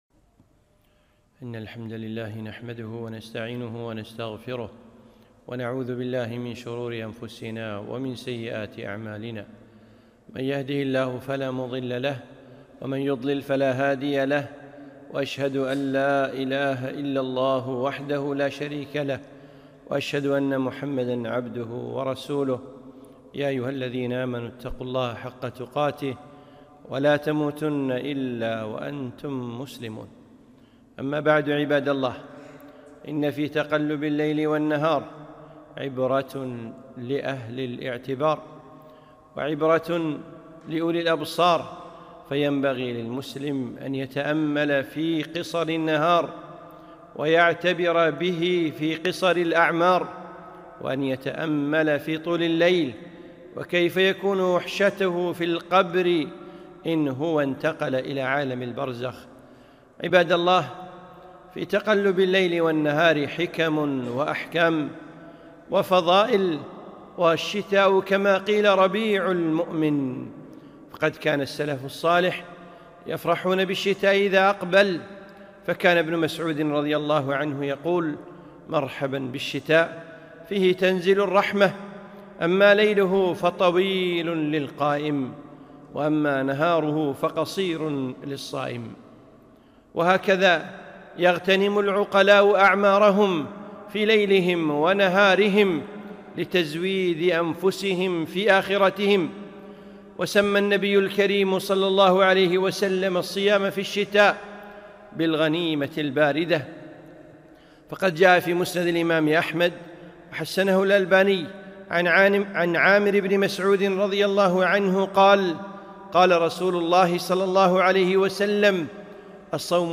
خطبة - الشتاء ربيع المؤمن 1443